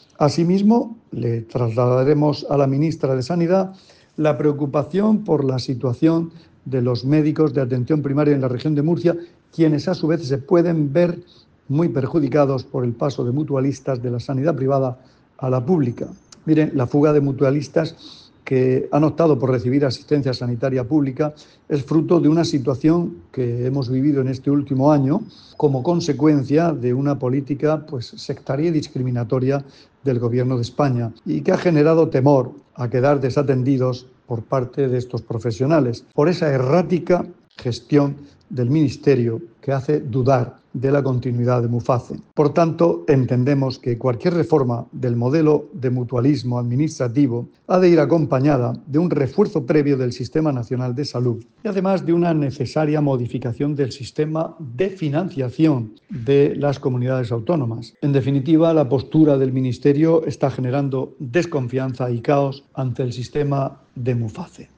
Sonido/ Declaraciones del consejero de Salud, Juan José Pedreño, sobre la situación de los médicos de familia, afectados por el traslado de pacientes desde Muface.